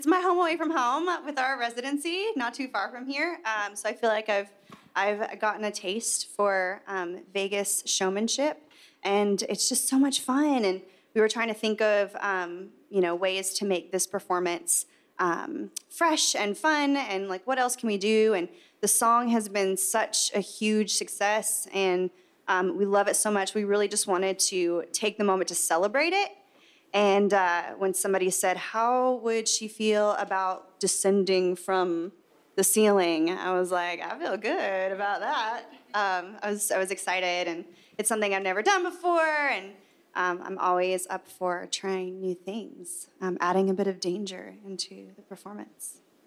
Audio / Backstage at this year's ACM Awards, Carrie Underwood talks about her performance with Jason Aldean on "If I Didn't Love You" on the show.